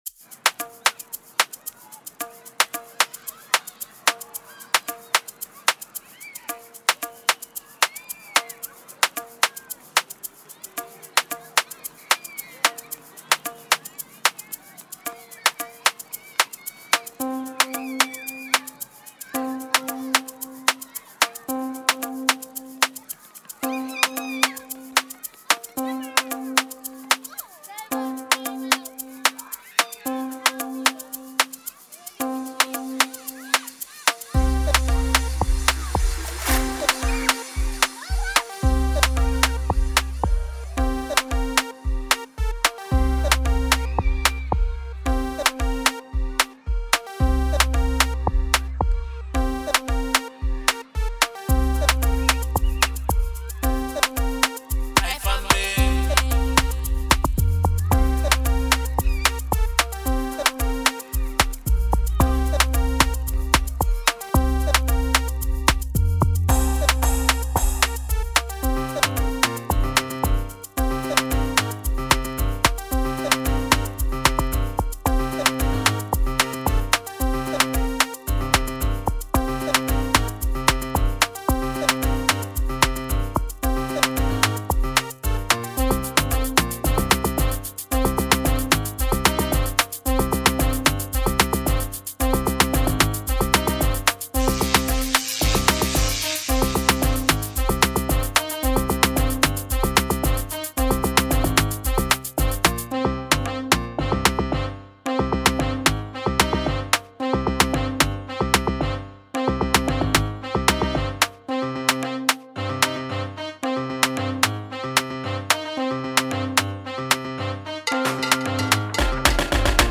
06:06 Genre : Amapiano Size